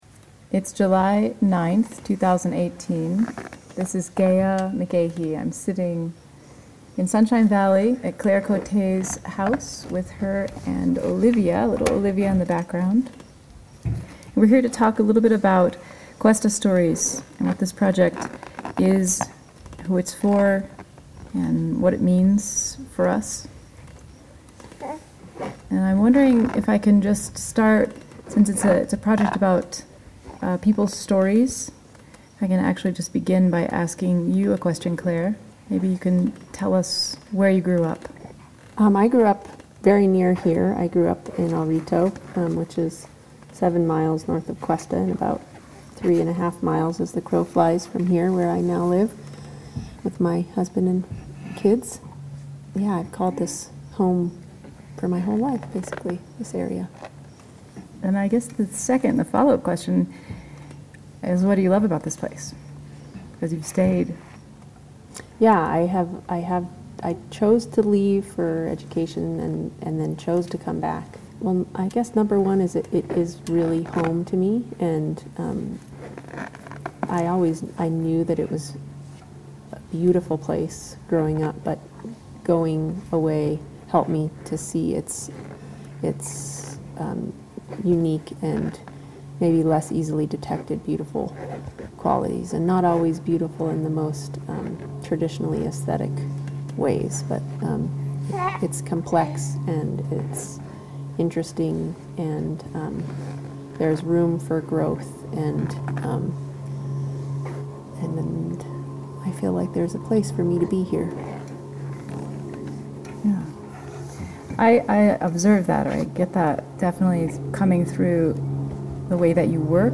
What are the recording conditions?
In the middle of summer 2018, when the land was bone dry and we dreamed of summer storms, the Gathering Memory: Object, Photos, and Story Community Workshop was held at the Questa VFW Hall.